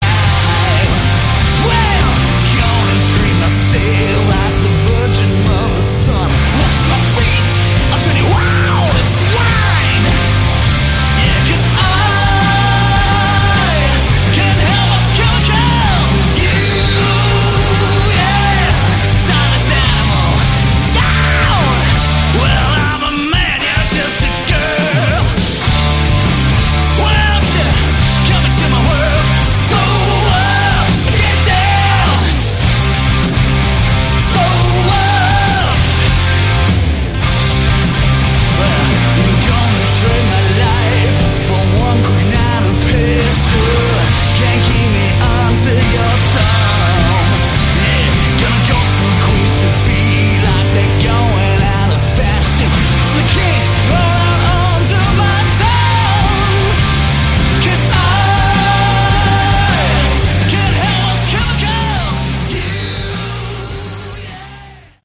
Category: Hard Rock
vocals, guitars
bass, backing vocals
drums, percussion